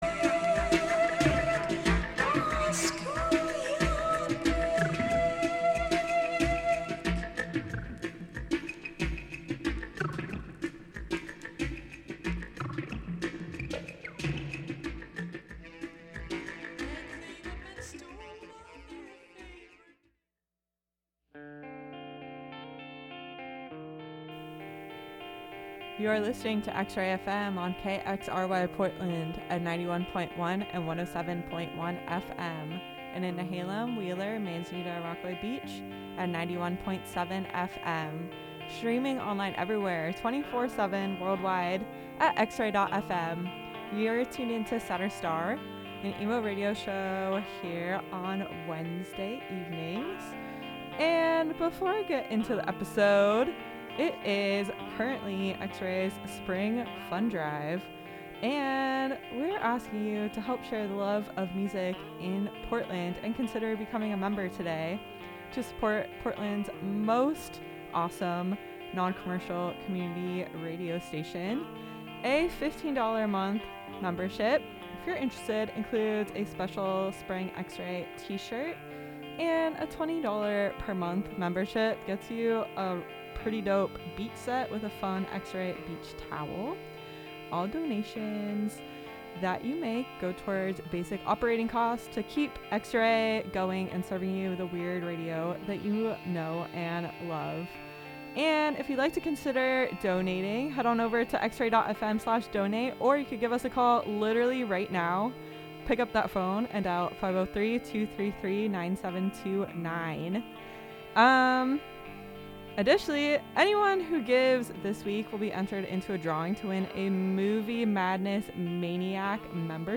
playing emo bands in **alphabetical order**